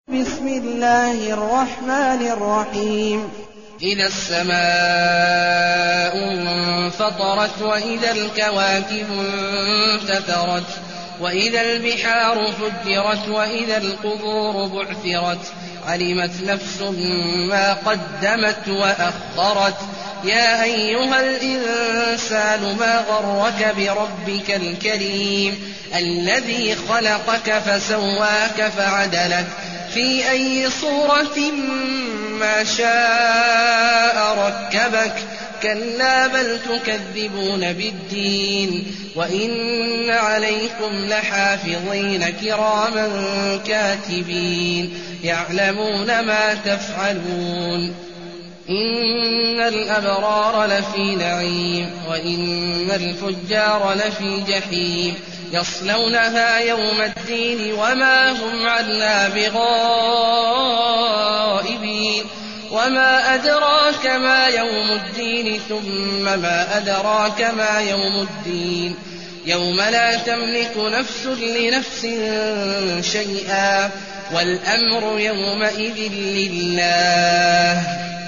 المكان: المسجد النبوي الشيخ: فضيلة الشيخ عبدالله الجهني فضيلة الشيخ عبدالله الجهني الانفطار